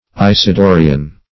Isidorian \Is`i*do"ri*an\, a.
isidorian.mp3